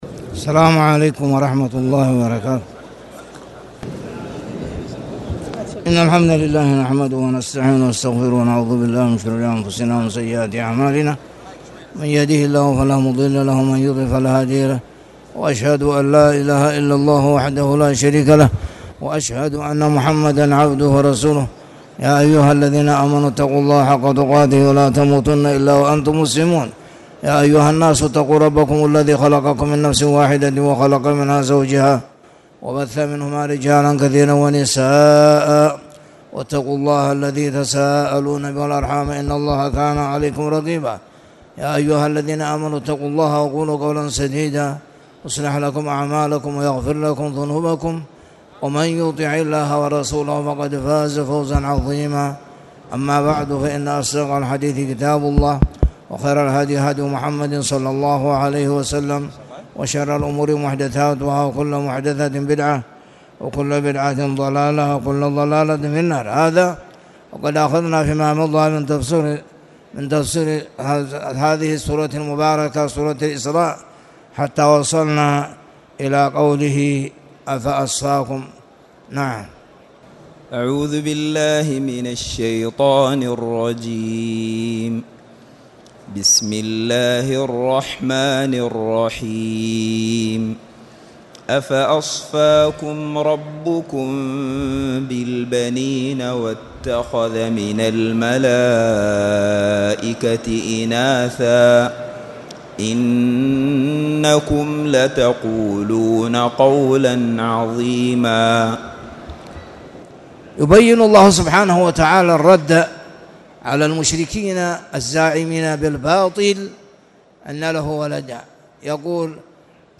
تاريخ النشر ٤ جمادى الأولى ١٤٣٨ هـ المكان: المسجد الحرام الشيخ